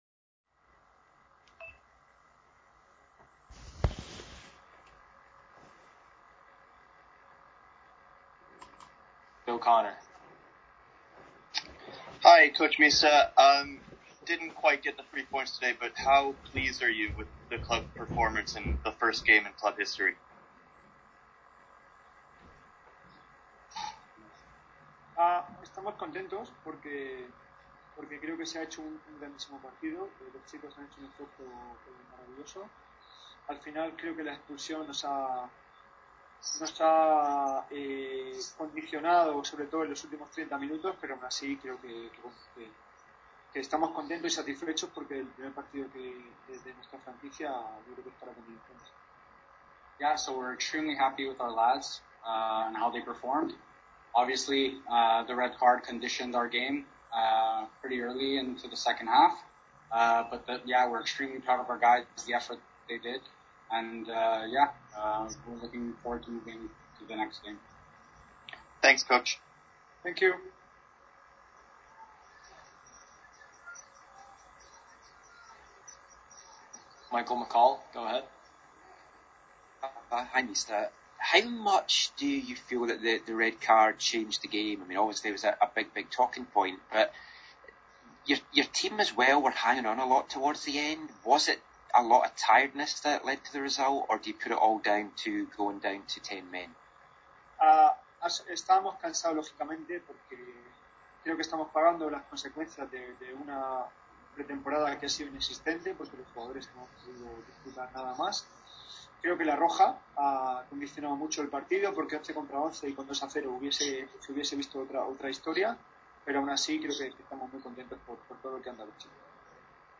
August 15, 2020...Complete audio of the York 9 FC vs Atletico Ottawa post game press conference
(33 minutes) Questions asked: Mista (Atletico Ottawa head coach) (questions and answers were translated from Spanish) How pleased were you to get the first points in club history?
(A Spanish question/answer not translated) Your thoughts on Francisco Acuna?
(Two French questions not translated) How will the future go with your team as an entirely new franchise?